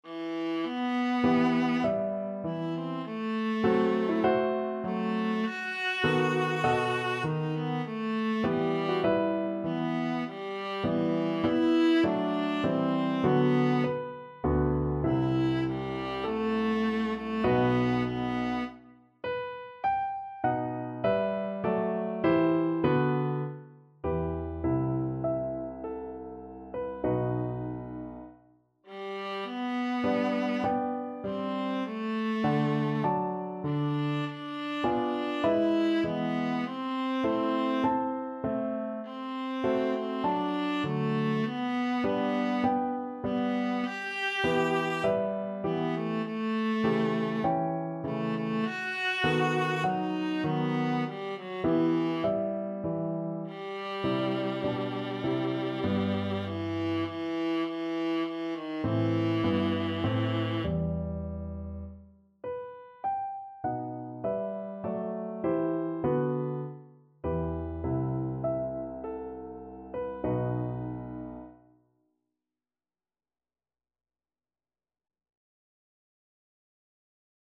Largo =c.100
Classical (View more Classical Viola Music)